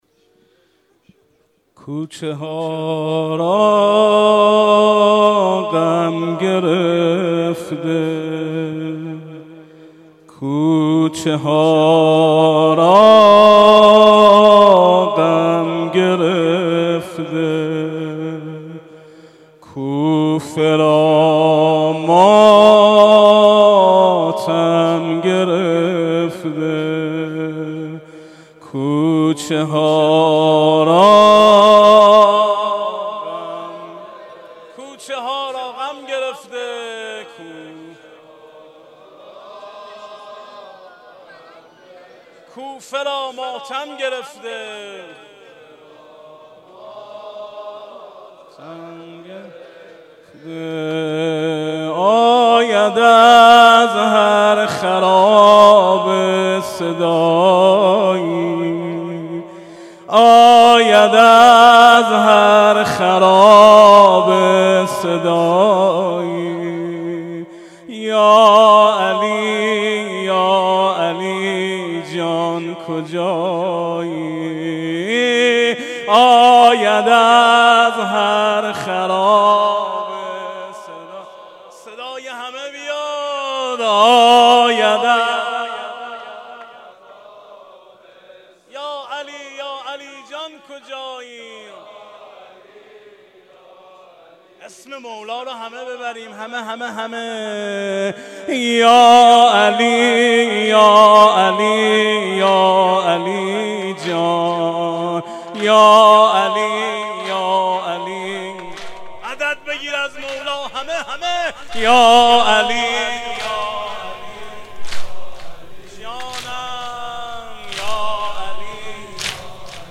03.nohe.mp3